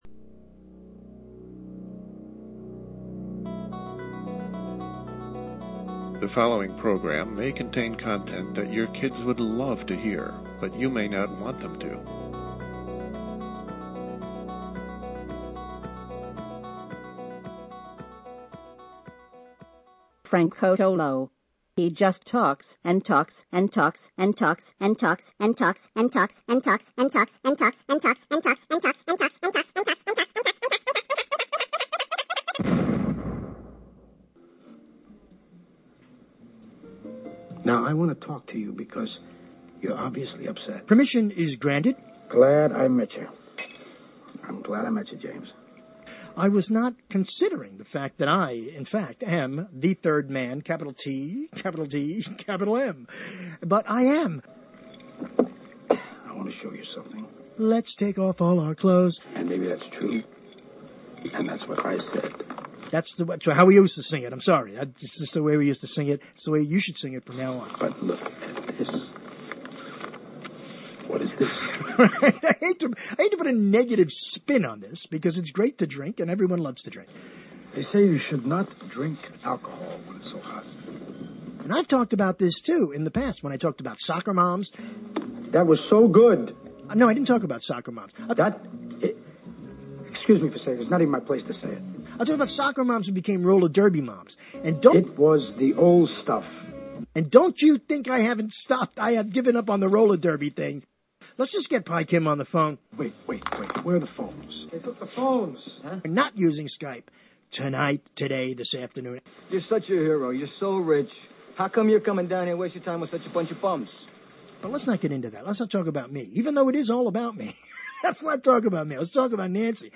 When two authors are cast adrift to pontificate in the discussion arena, the result is some of the more fascinating talk radio available in the new broadcast theater.